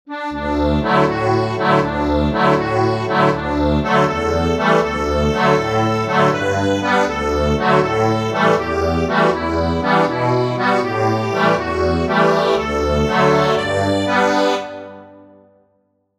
Instrument: accordion